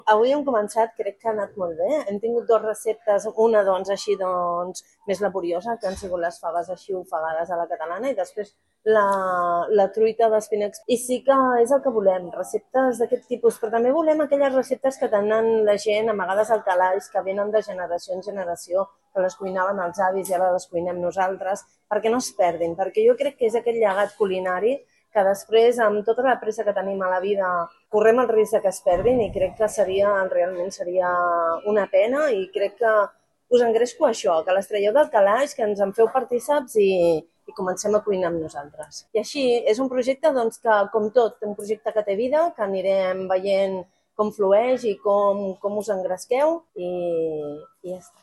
Montserrat Salas, regidora de Gent Gran